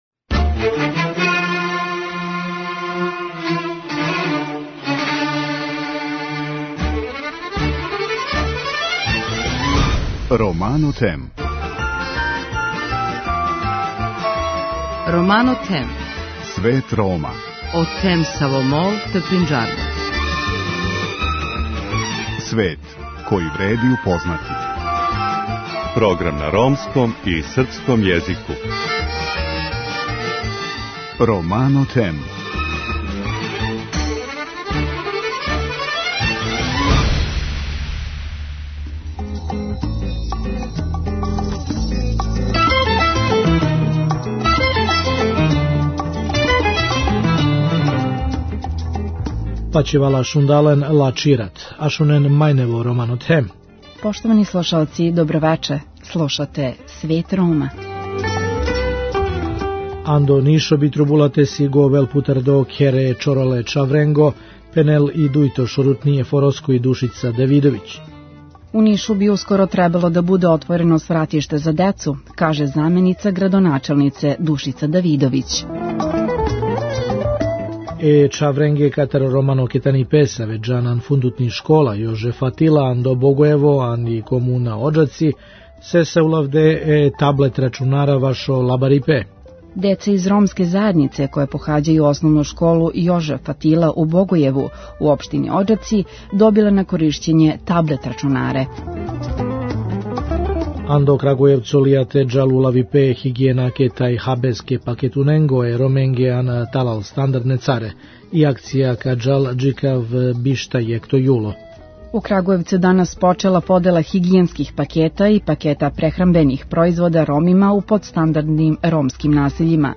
Vesti na romskom jeziku